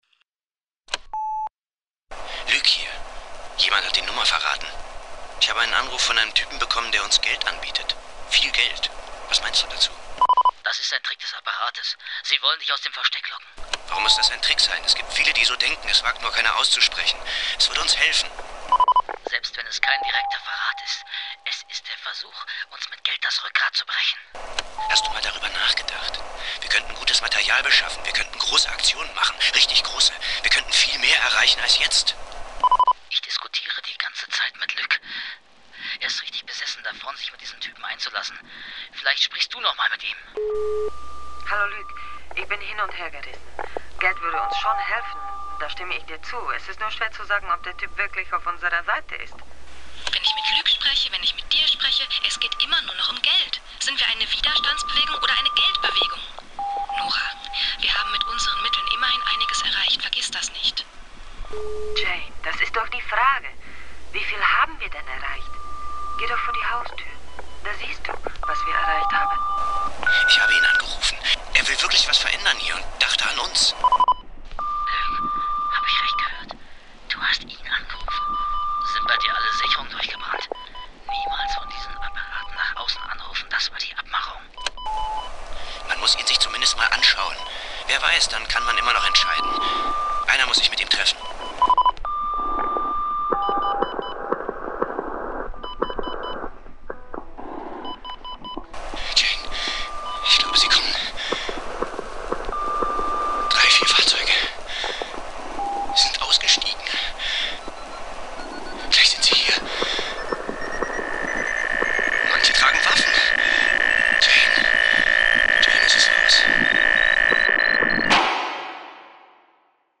Ich habe übrigens ausschließlich Klänge von Anrufbeantwortern für die Produktion verwendet. Also Tuten, Spulen, Rauschen, Piepen, Knacksen und ähnliches.
Ausschnitte der Radiofassung: